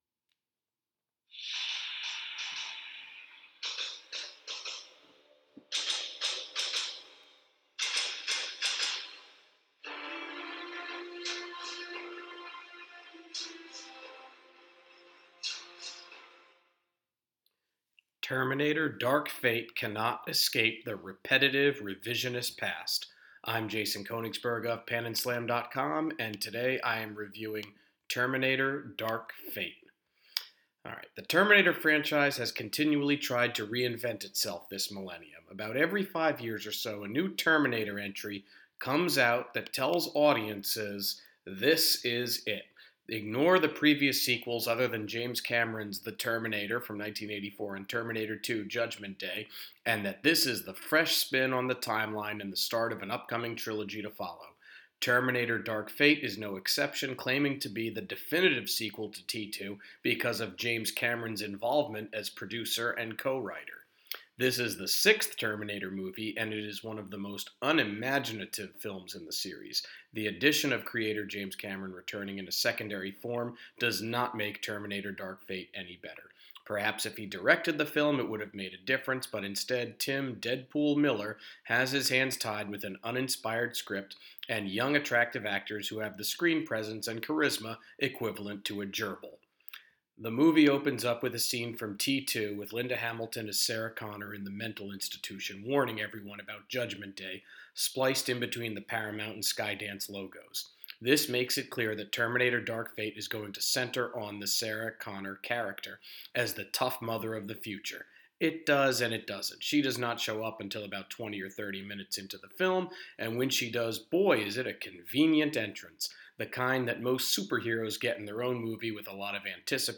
Movie Review: Terminator: Dark Fate